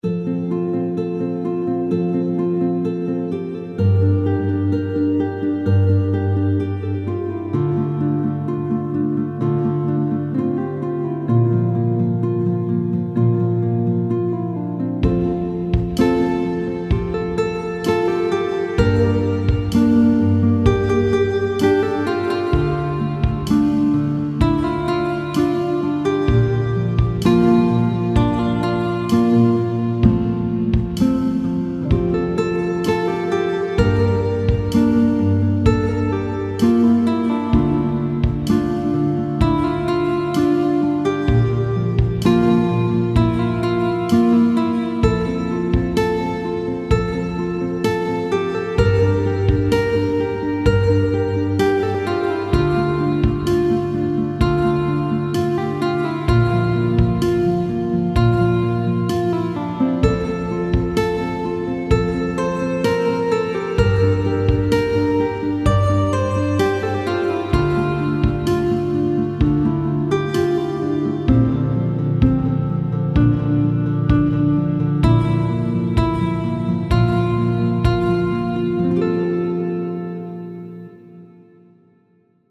Accordage : Standard